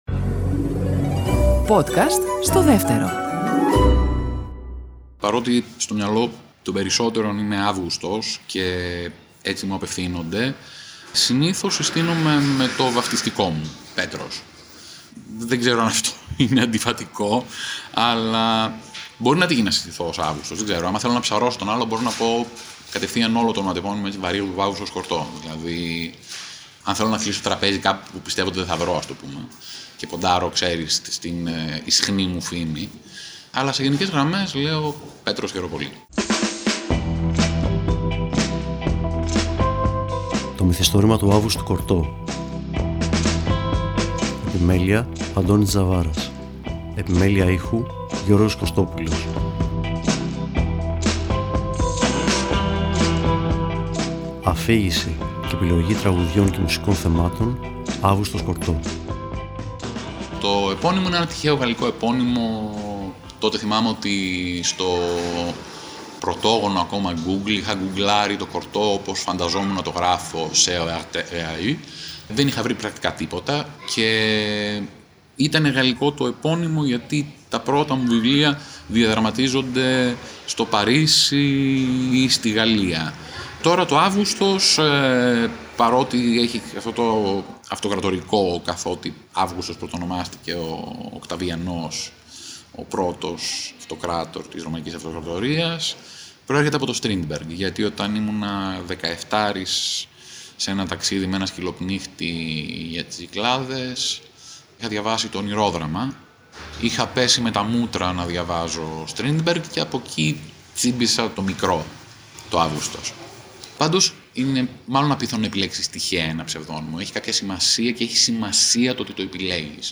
Το Μυθιστόρημα του Αύγουστου Κορτώ είναι σκληρό, τρυφερό και συναρπαστικό όσο και τα μυθιστορήματά του. Μας το χαρίζει επενδεδυμένο με τις αγαπημένες του μουσικές και πασπαλισμένο με το διαβρωτικό του χιούμορ – ένα πραγματικό δώρο, από έναν από τους πιο ταλαντούχους αφηγητές της εποχής μας.